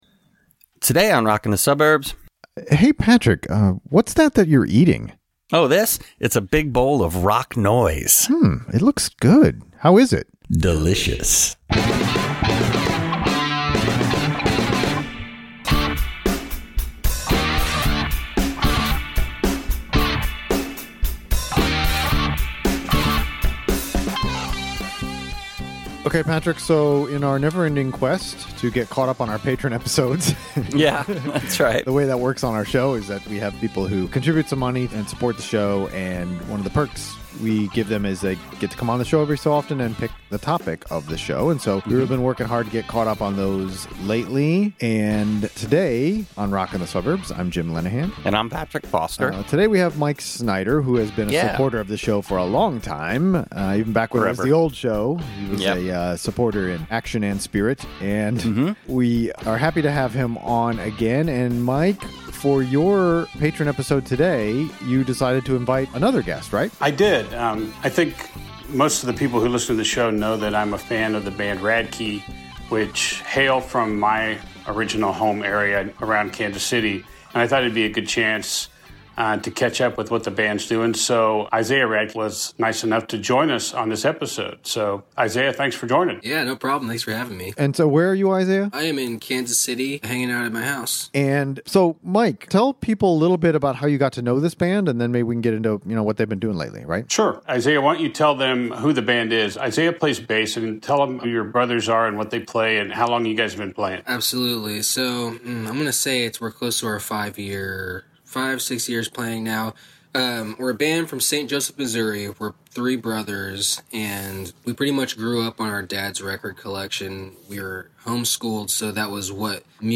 Radkey - Interview